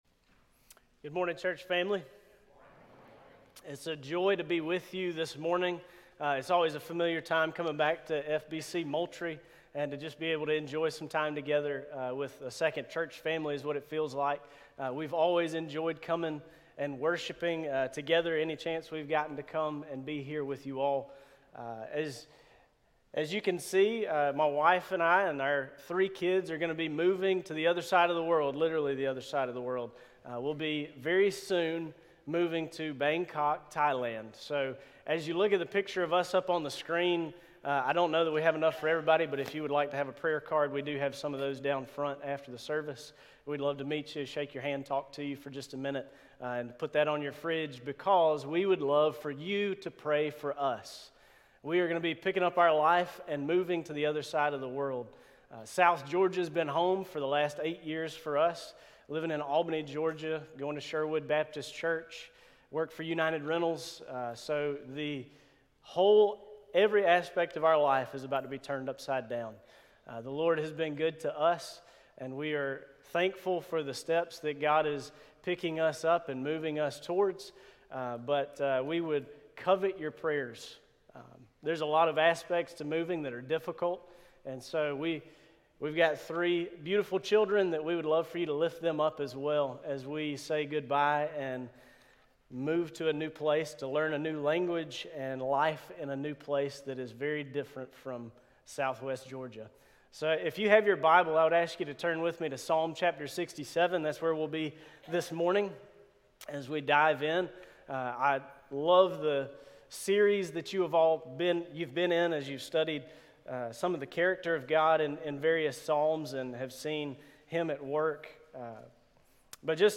New Year's Resolution: Daily Bible Reading Sermon